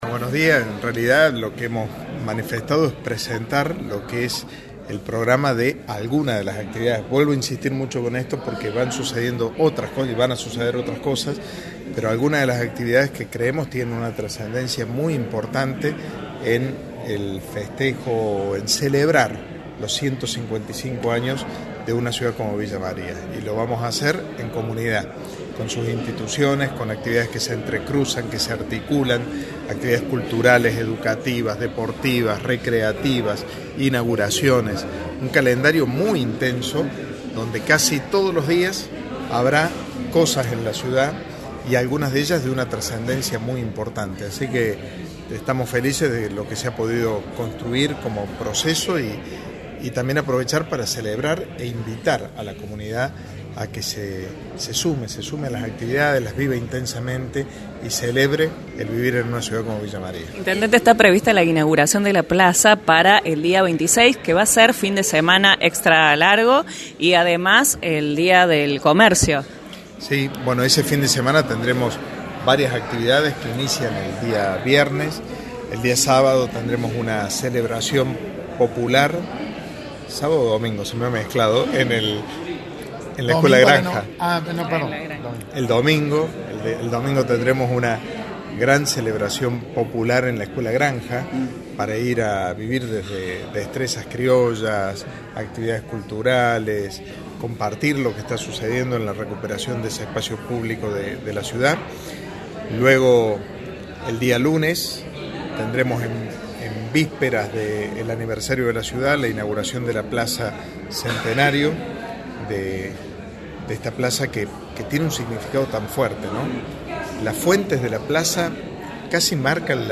En conferencia de prensa, el municipio villamariense, con el intendente Martín Gill a la cabeza, presentó lo que serán varias actividades que se concretarán en el marco del aniversario N° 155 de la ciudad de Villa María.